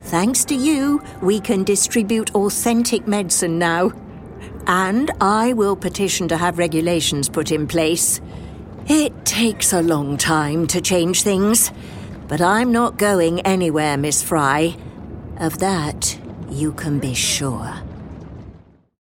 British ● General British ● Heightened RP ● RP Adult ● Senior
Audio Drama ● Videogame